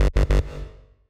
InteractionFailed.wav